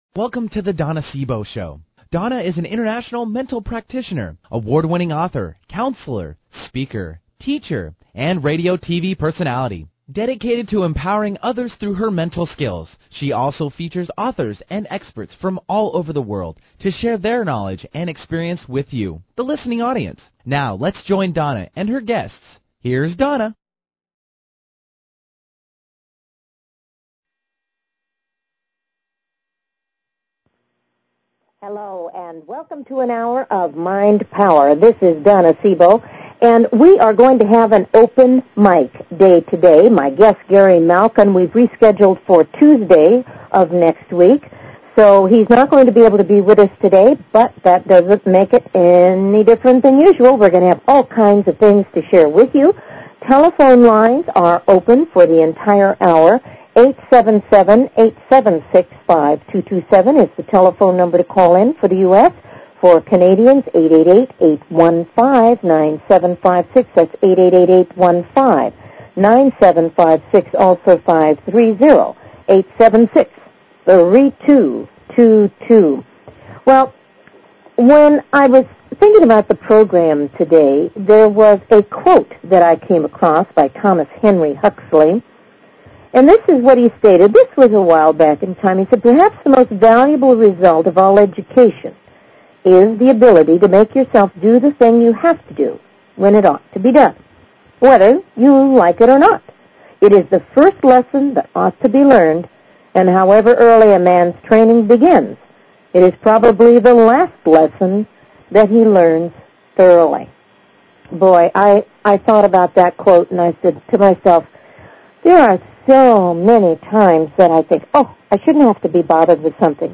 We'll be hearing some of his fabulous works.